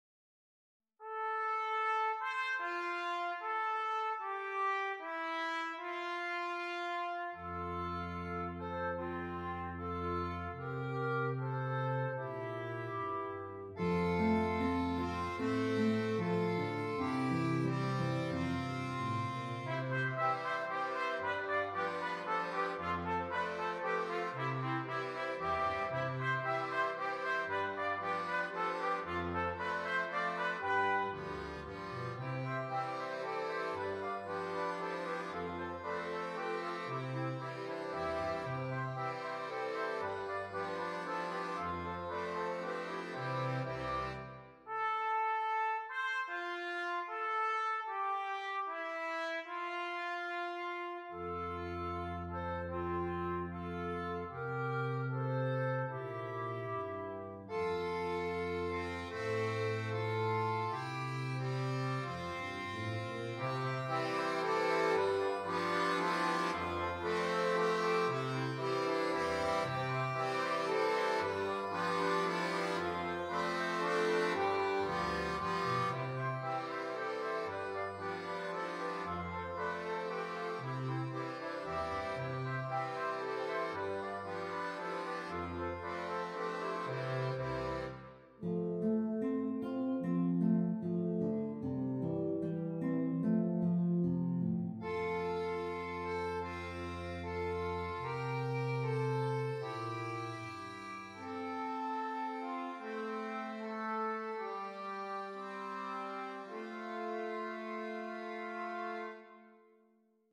Klarinettenmusig…
Klarinetten Quartett / Quintett